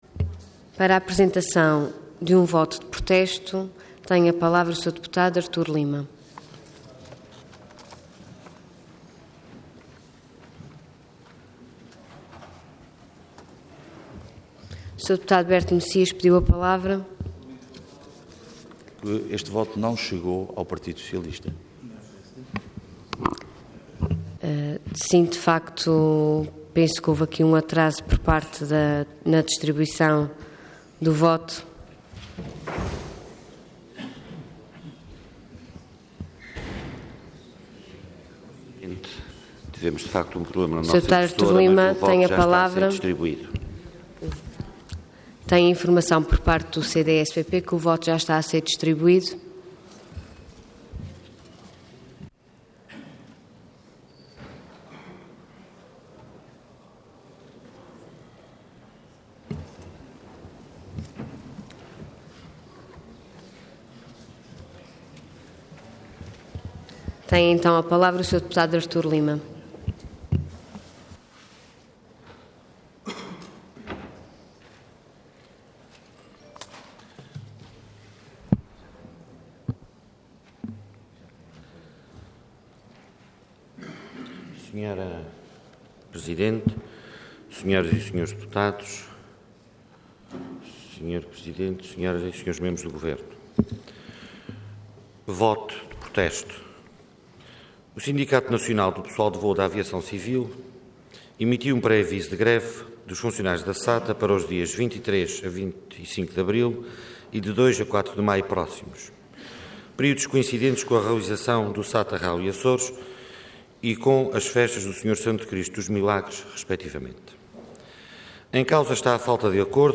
Intervenção Voto de Protesto Orador Artur Lima Cargo Deputado Entidade CDS-PP